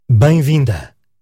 A collection of useful phrases in European Portuguese, the type of Portuguese spoken in Portugal.